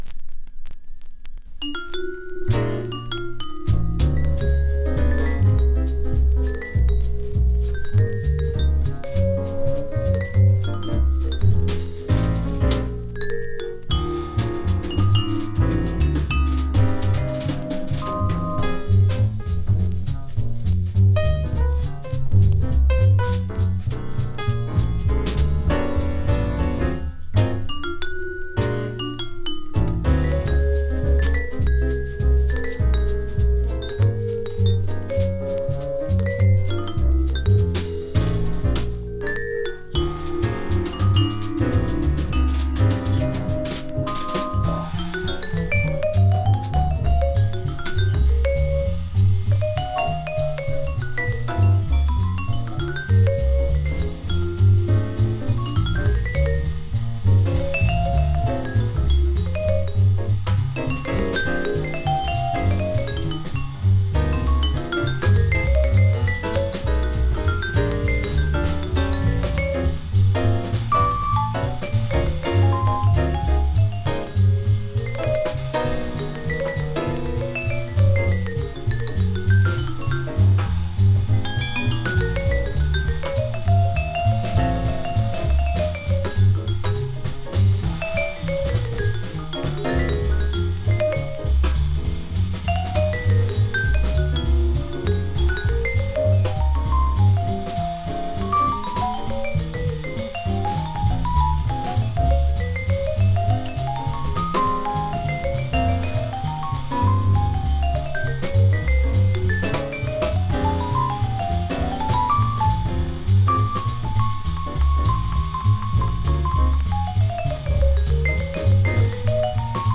sticking strictly to straight-ahead jazz